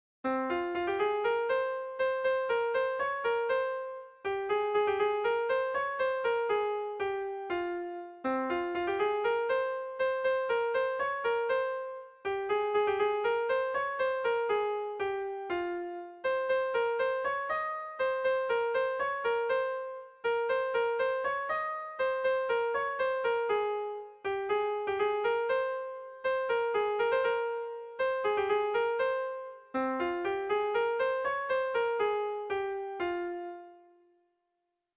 Irrizkoa
Zuberoa < Basque Country
Hamahirukoa, txikiaren moldekoa, 8 puntuz (hg) / Zortzi puntukoa, txikiaren moldekoa (ip)